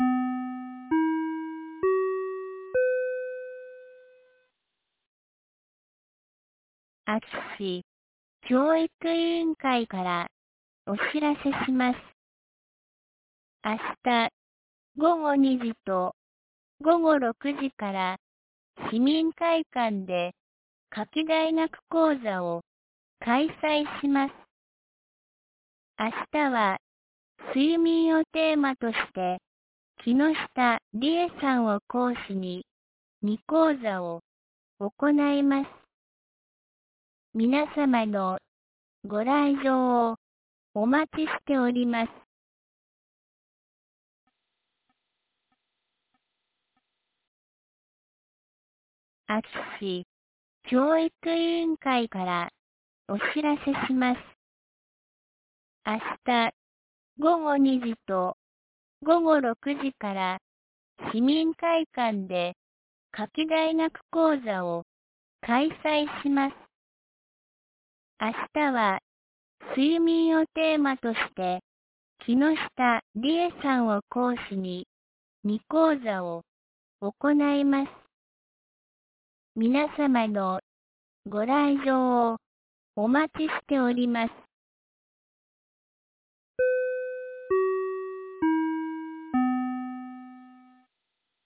2024年08月18日 17時11分に、安芸市より全地区へ放送がありました。